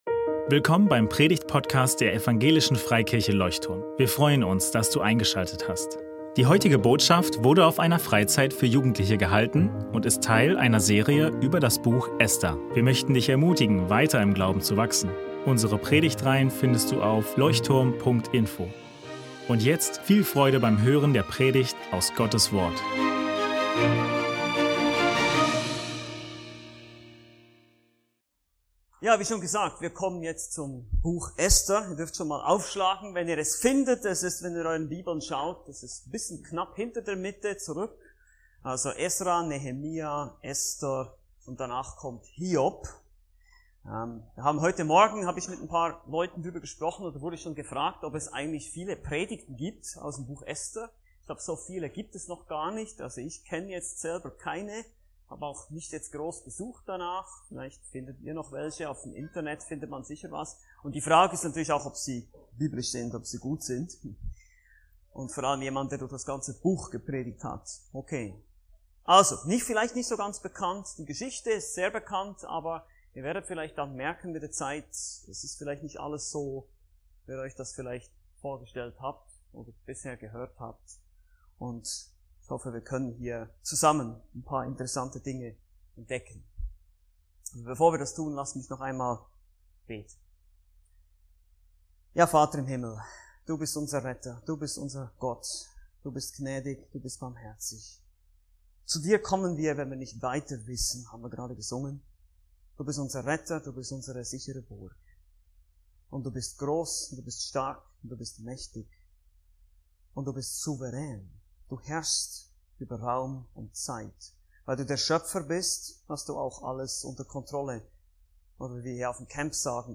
Absage mit Folgen ~ Leuchtturm Predigtpodcast Podcast